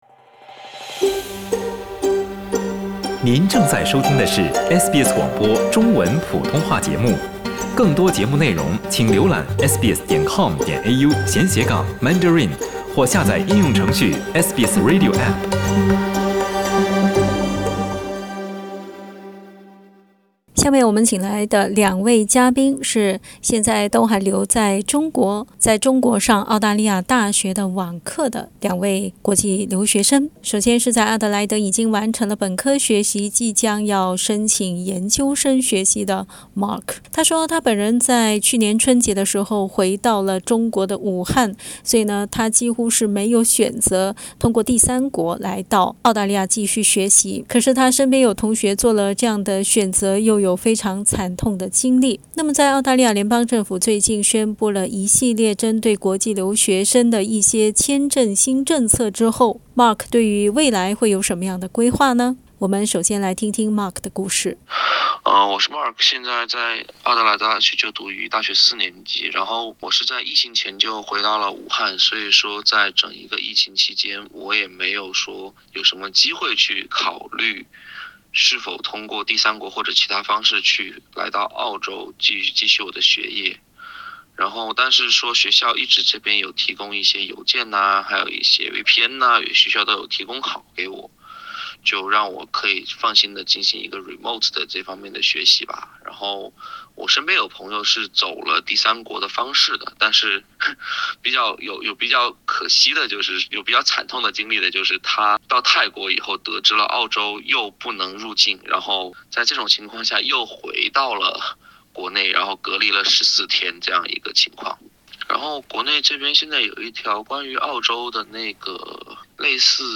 这两位留学生的经历也许代表了很多国际留学生的遭遇，我们还是来听他们亲自讲述，到底他们各自经历了什么？